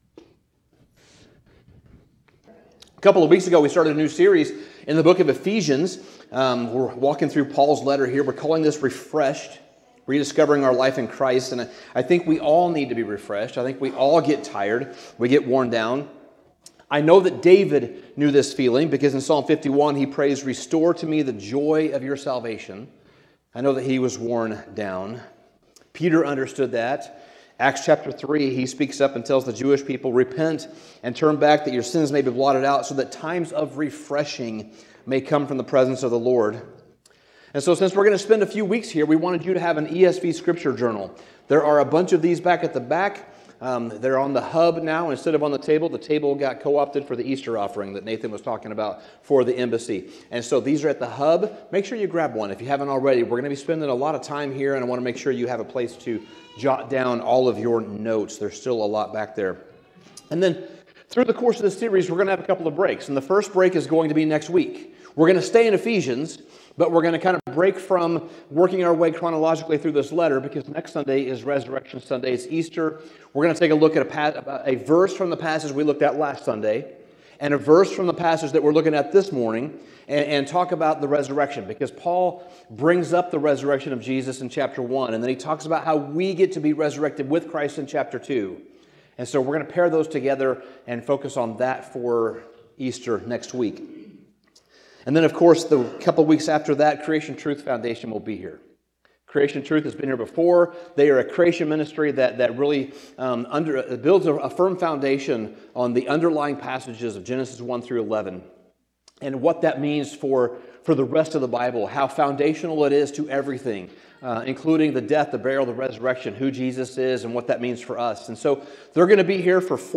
Sermon Summary There is a kind of clarity that only comes when we remember where we came from.